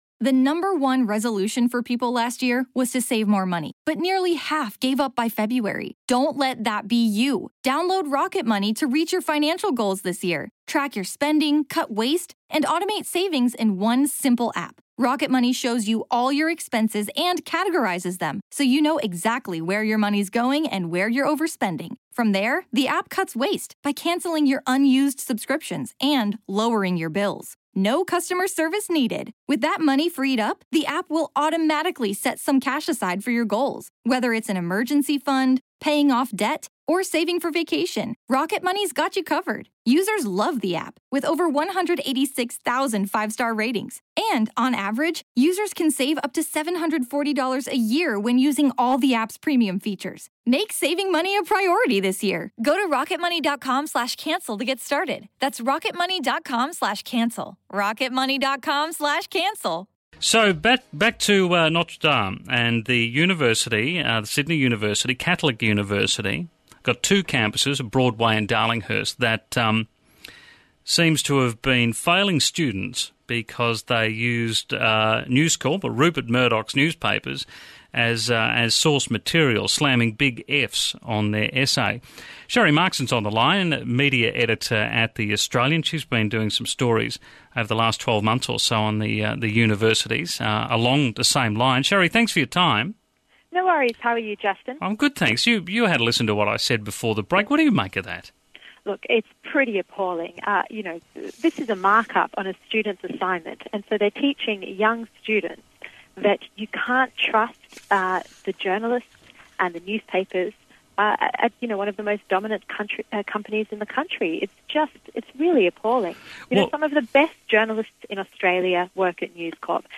Editorial: Murdoch & education propaganda